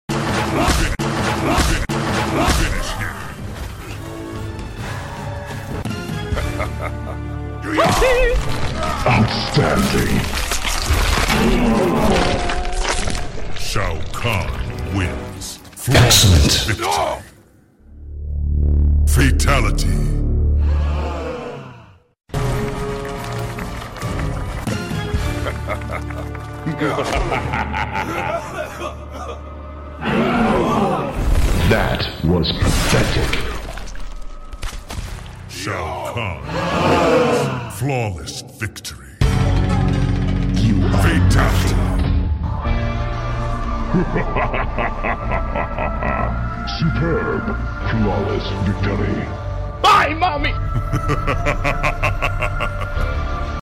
Mortal Kombat 9 Shao Kahn sound effects free download